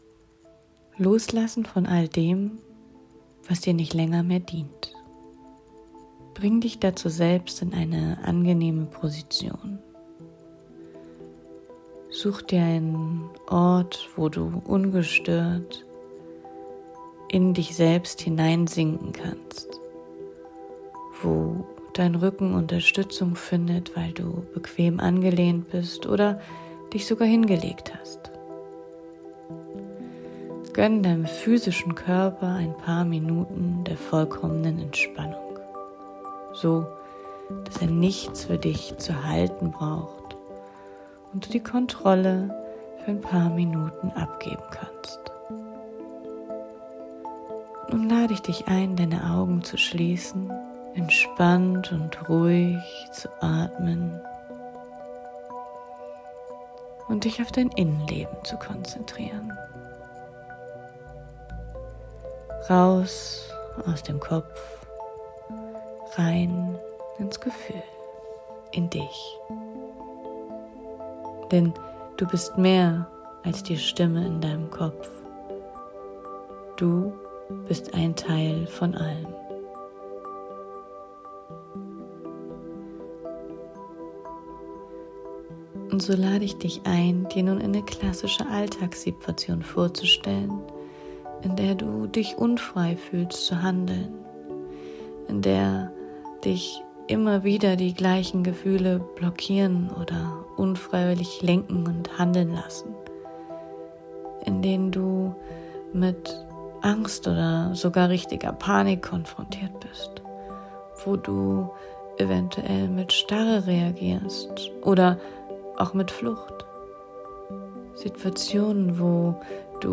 Geführte Trancereise.